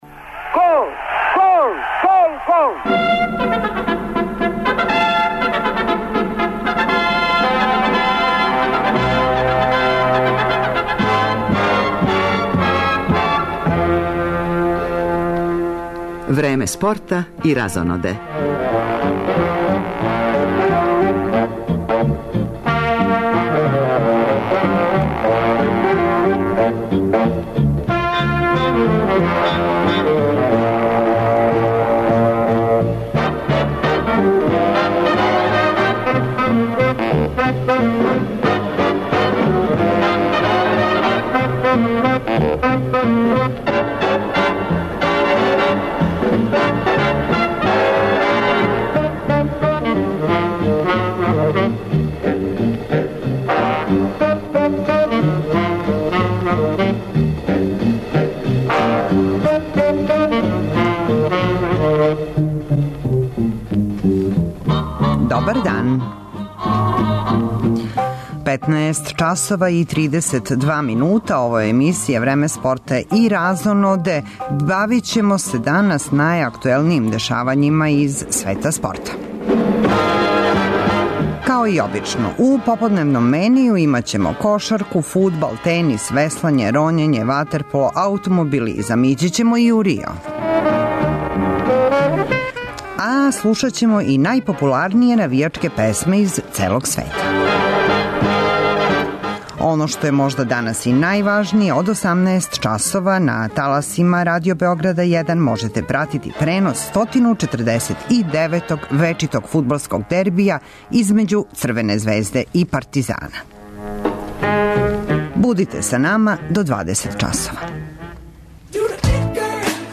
Први пут на нашим таласима, слушаоци ће моћи да чују најбоље навијачке песме из целог света: Русије, Јапана, Велике Британије, Колумбије, Чилеа, Јужне Африке, Индонезије, Бразила....
Кошаркаши Србије настављају такмичење у четвртфиналу Првенства Европе са првог места, у недељу се игра са Финском. Чућемо шта су наши репортери забележили на овом такмичењу.